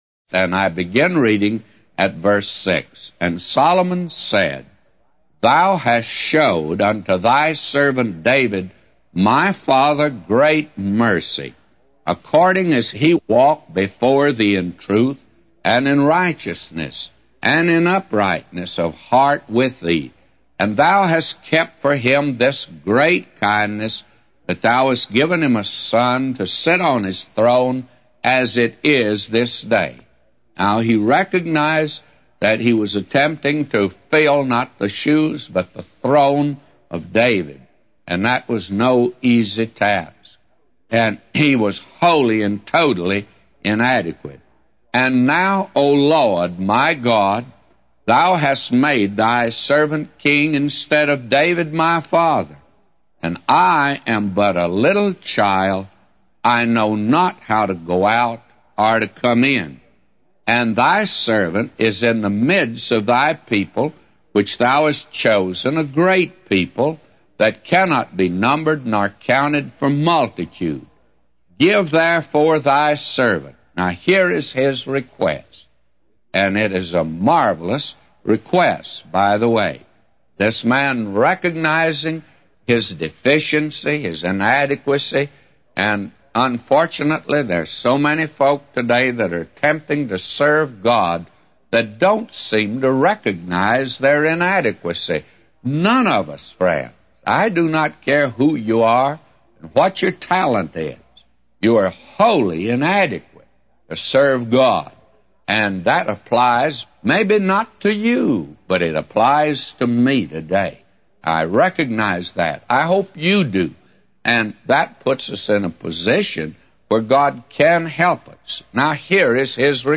A Commentary By J Vernon MCgee For 1 Kings 3:6-999